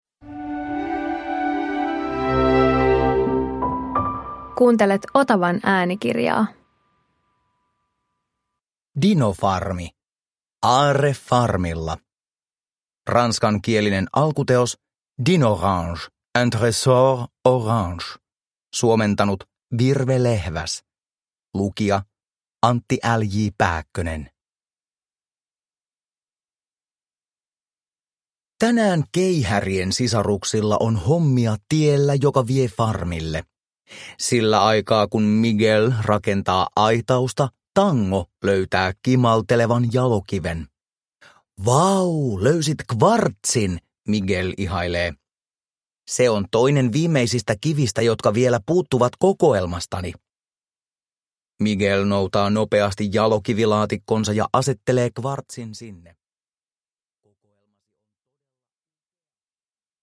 Dinofarmi - Aarre farmilla – Ljudbok – Laddas ner